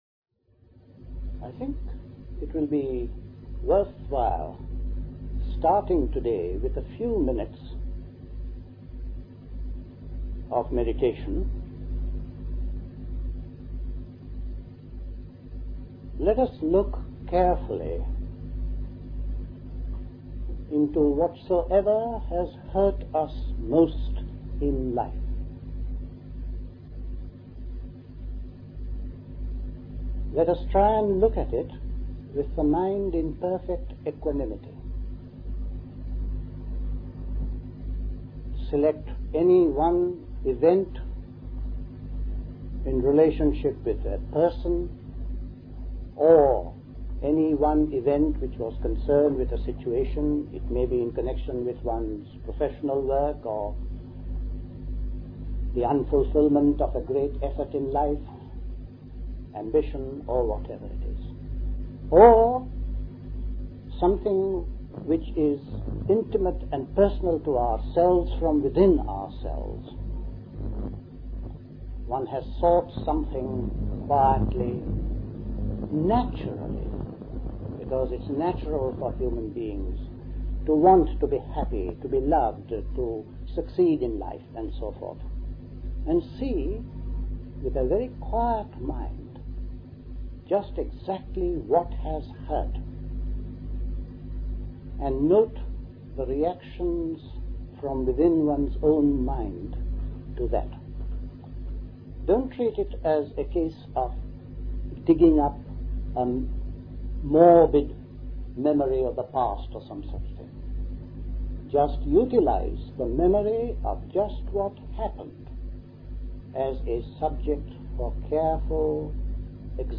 at Dilkusha, Forest Hill, London